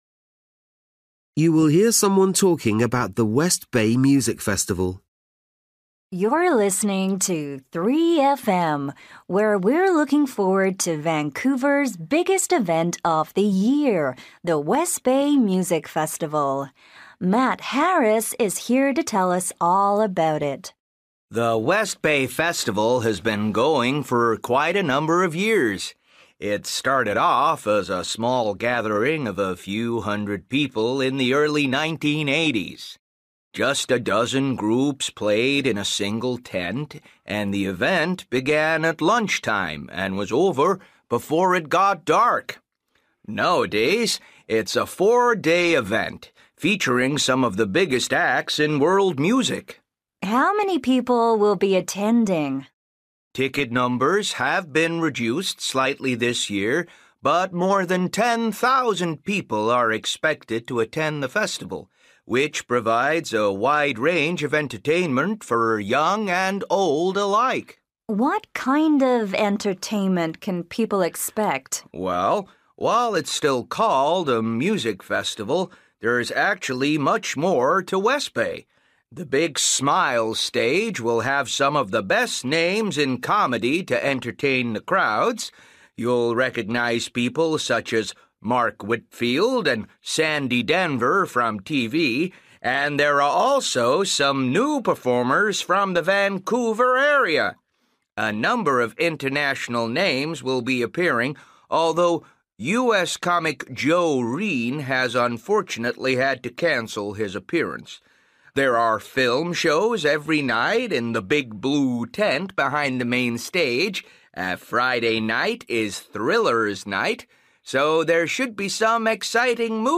You will hear someone talking about the Westbay Music Festival.